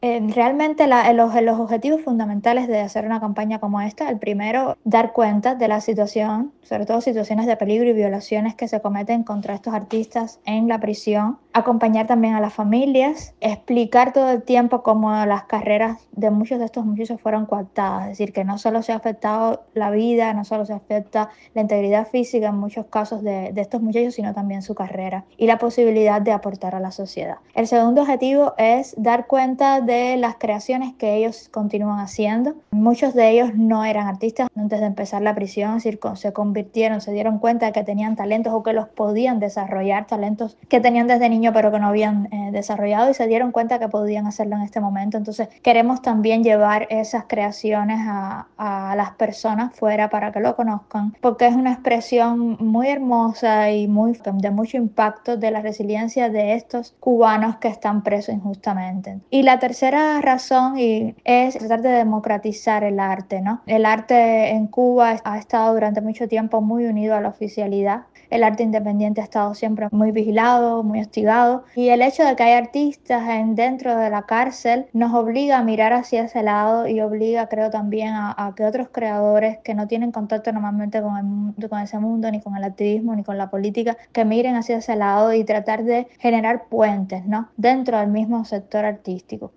Las principales informaciones relacionadas con Cuba, América Latina, Estados Unidos y el resto del mundo de los noticieros de Radio Martí en la voz de nuestros reporteros y corresponsales